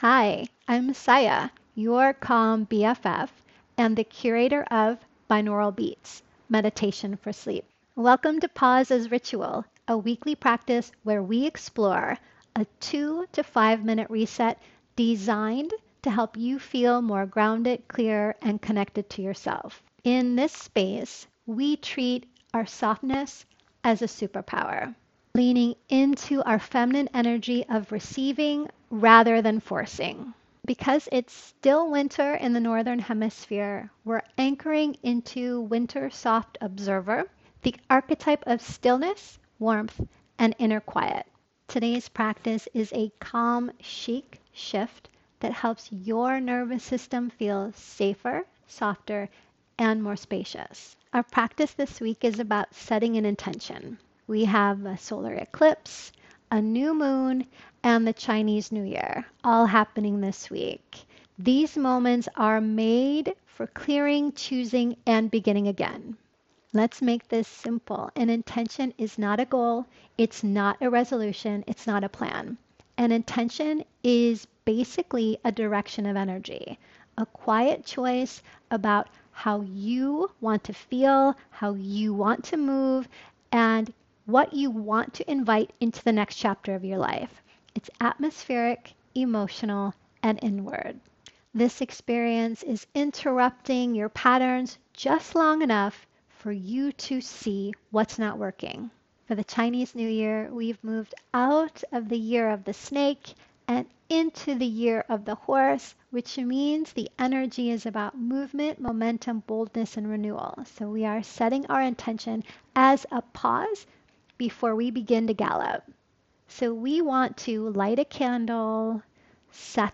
Binaural Beats Meditation for Sleep
This podcast is part of Ritual Era Meditation — a guided ritual experience